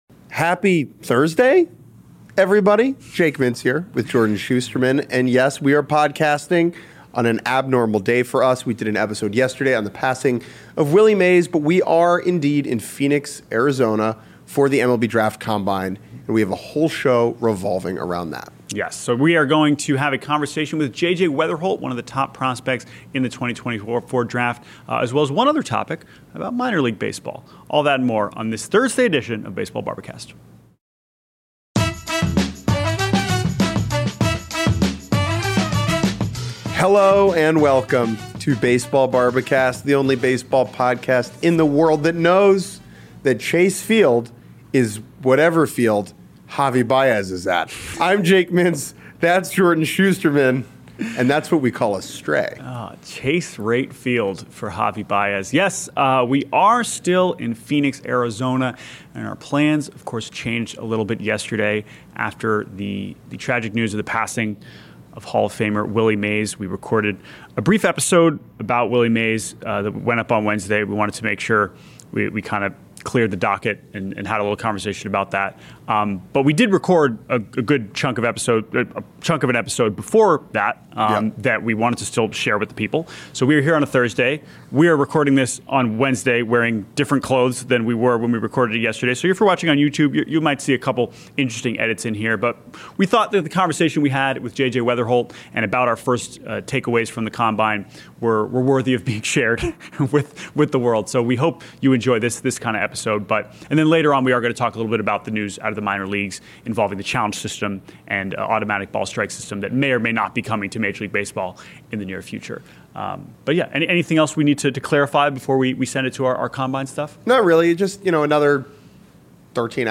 The Baseball Bar-B-Cast crew is on the road for this episode of the podcast, coming to you live from Chase Field in Arizona where Major League Baseball is holding the 2024 edition of the Draft Combine.